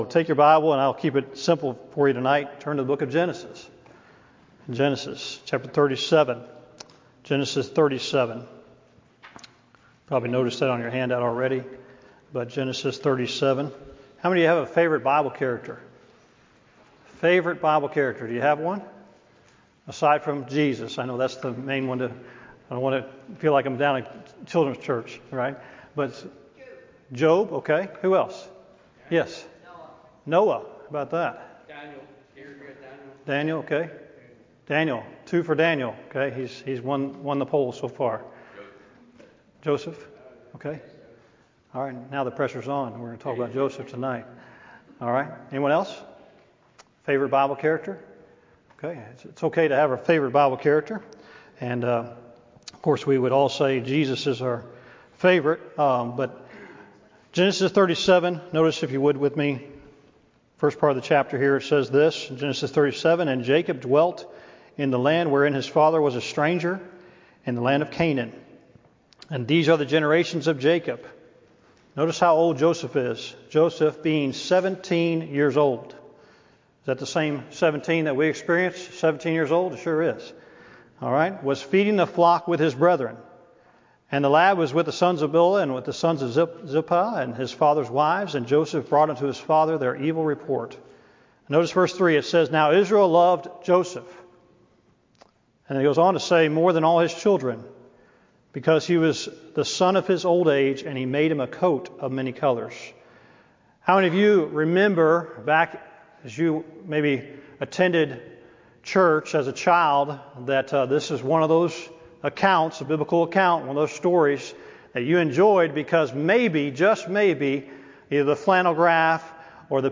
Wednesday Night Bible Study